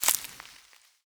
harvest_1.wav